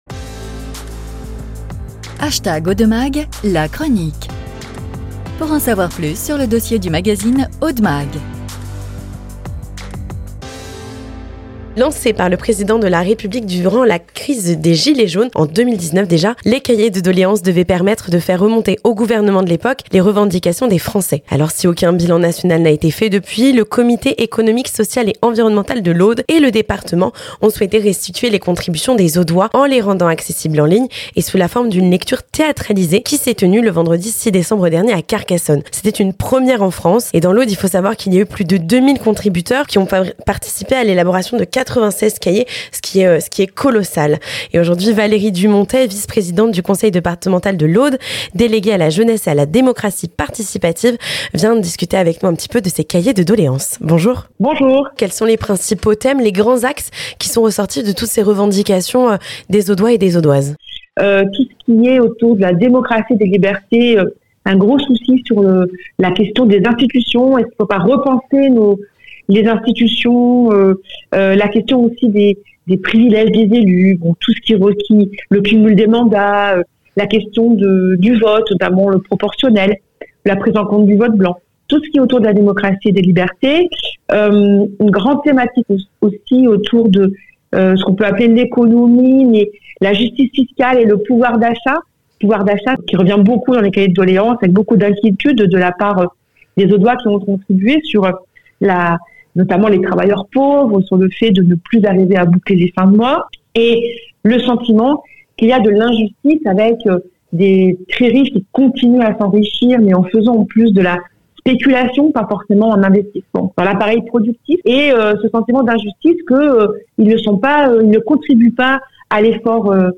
Interview - Cahiers de doléances, qu'ont écrit les Audoises et les Audois ?